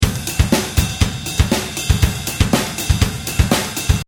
| jazz style drum loop |